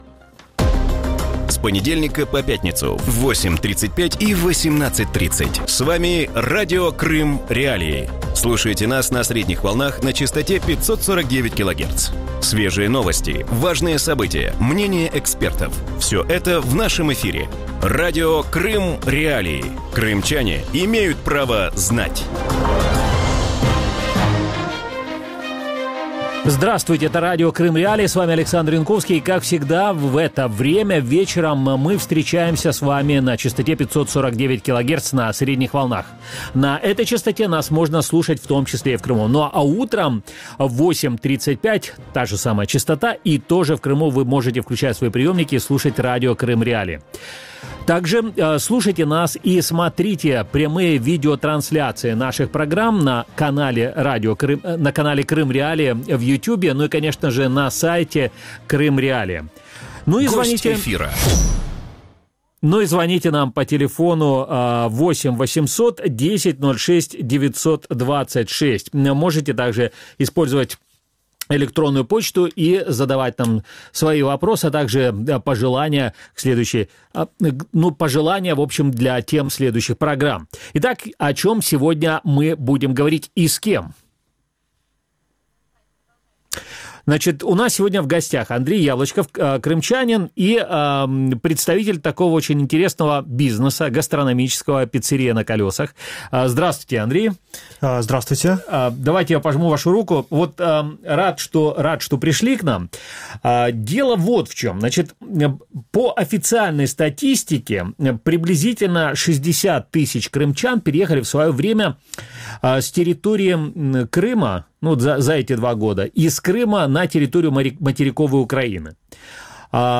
У вечірньому ефірі Радіо Крим.Реалії обговорюють життя кримчан, які переїхали з півострова на материкову Україну. Як живеться кримчанам в нових умовах, чи можна відкрити успішний бізнес в Україні і як вимушених переселенців сприймають в українських містах?